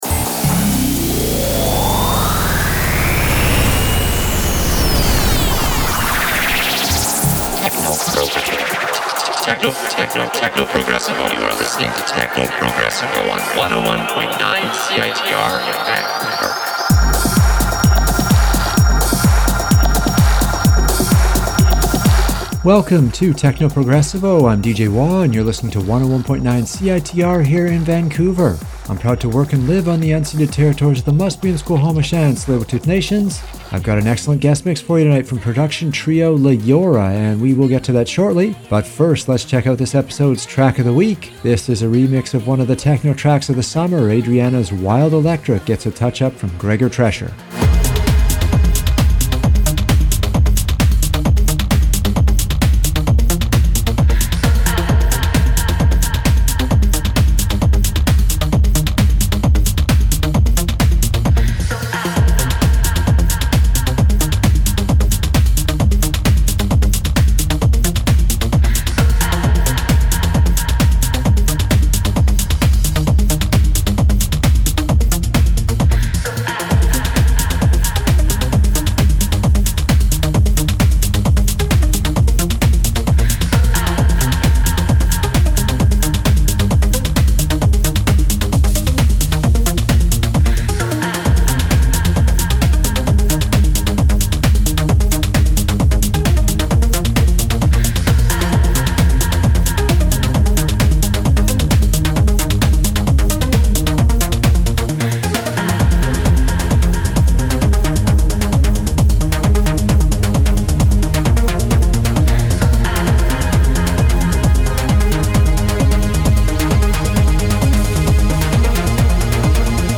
Guest Mix